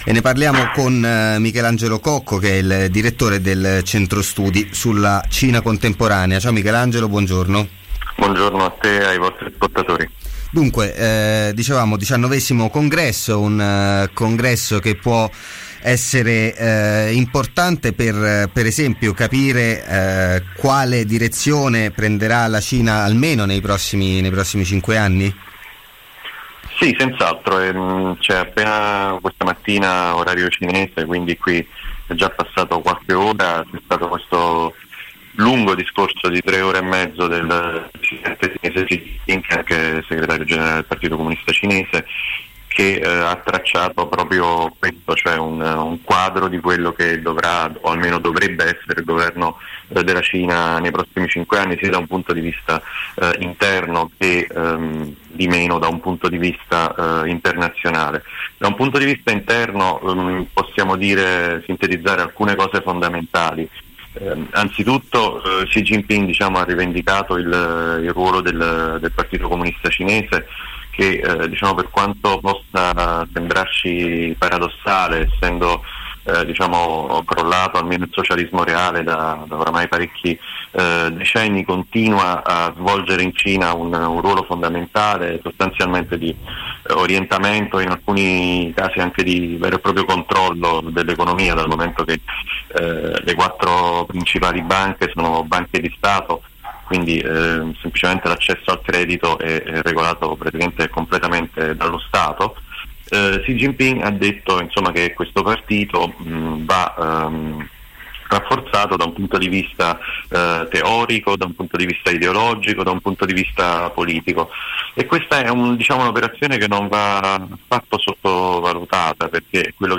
Al via il XIX congresso del Partito Comunista Cinese: intervista